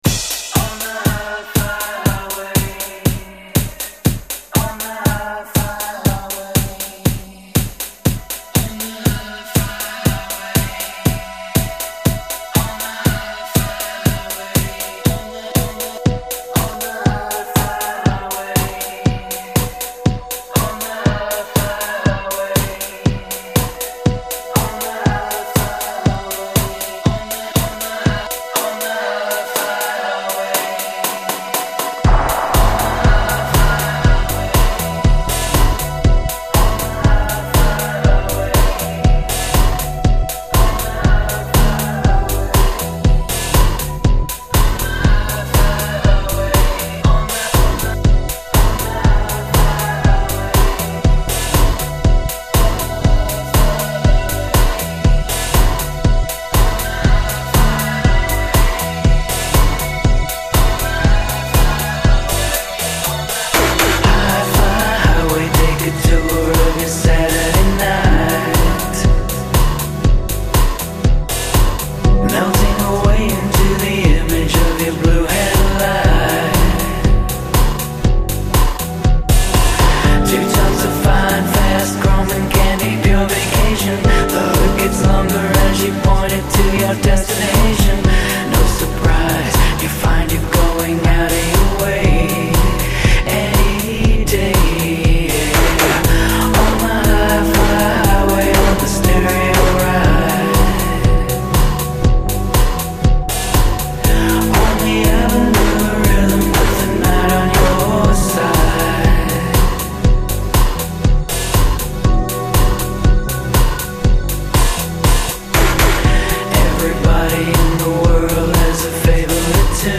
Recapturing AM radio in pop songs with lush arrangements.